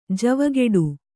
♪ javageḍu